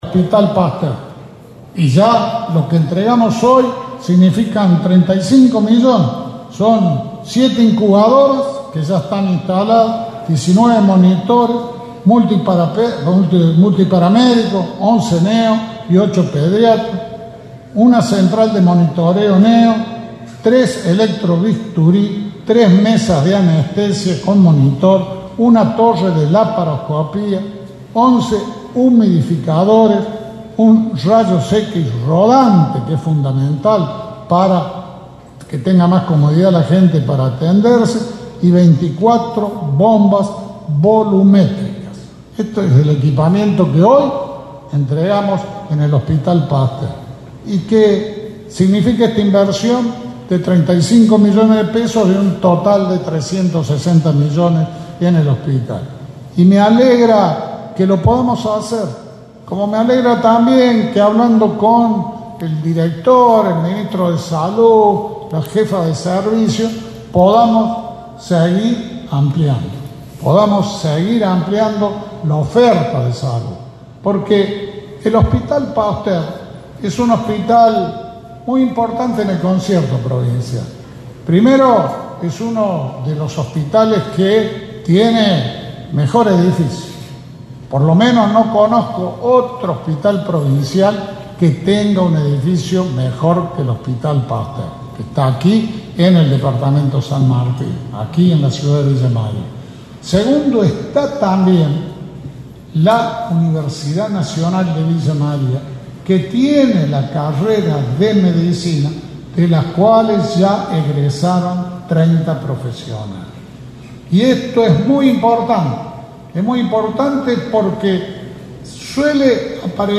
AUDIO – JUAN SCHIARETTI, GOBERNADOR DE CÓRDOBA.